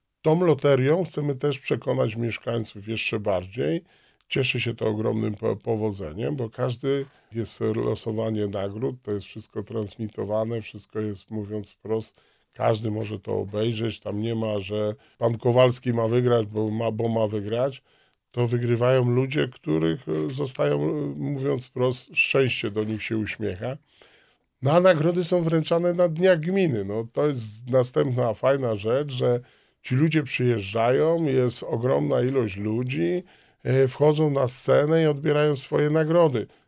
Mówi Wojciech Błoński, wójt Gminy Długołęka.